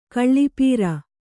♪ kaḷḷi pīra